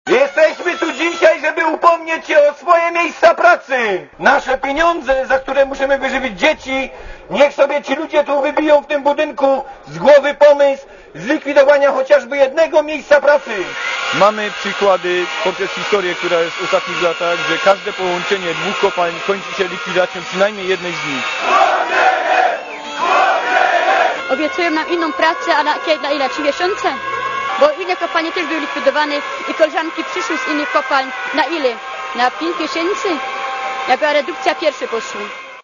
Komentarz audio